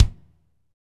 Index of /90_sSampleCDs/Northstar - Drumscapes Roland/KIK_Kicks/KIK_A_C Kicks x
KIK A C K0CL.wav